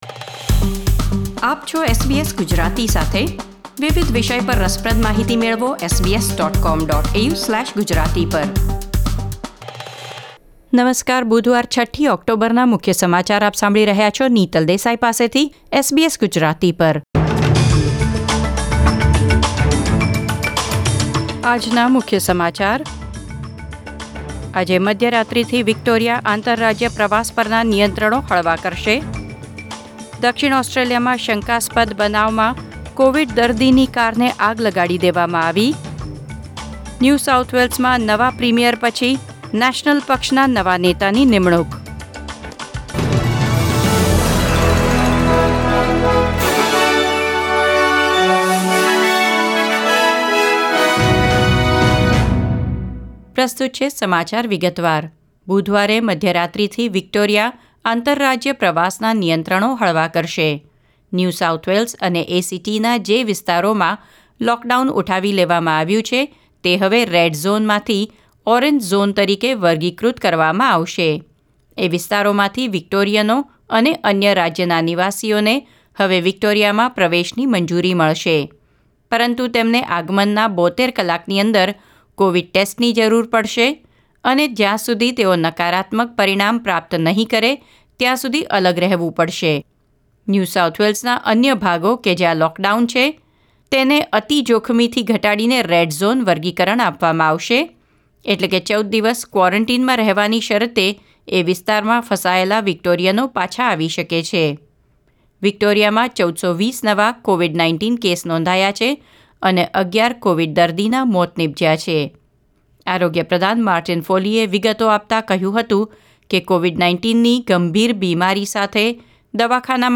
SBS Gujarati News Bulletin 6 October 2021